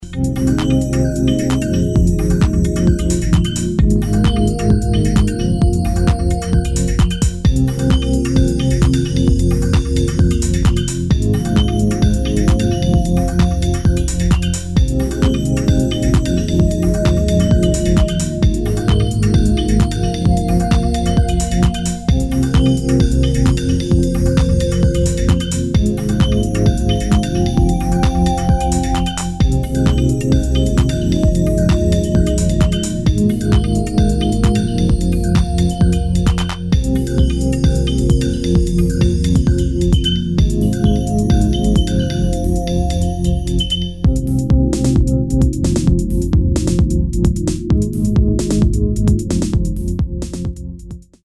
[ TECHNO / MINIMAL ]